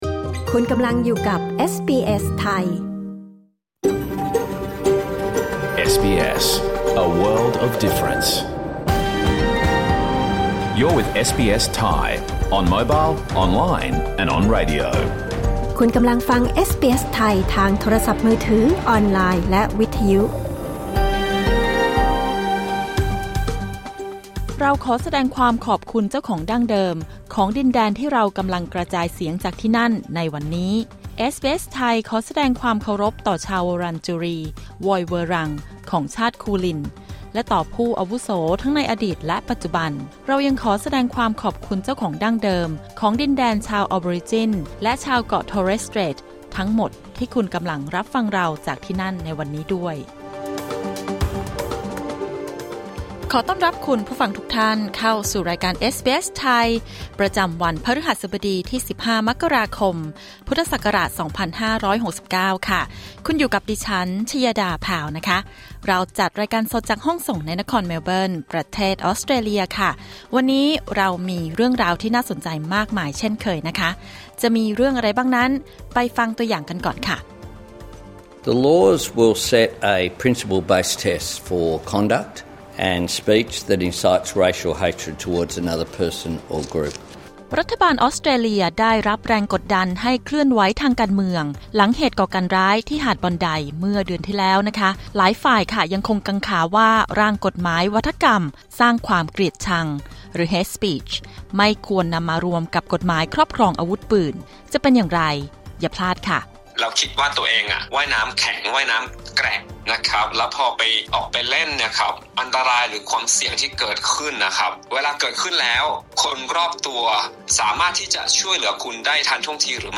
รายการสด 15 มกราคม 2569